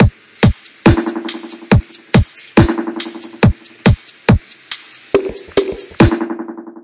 Lofi Pad
描述：lofi pad made with serum and some rc20 slapped on it,send links if you do anything with it lol
标签： 100 bpm LoFi Loops Pad Loops 1.62 MB wav Key : D FL Studio
声道立体声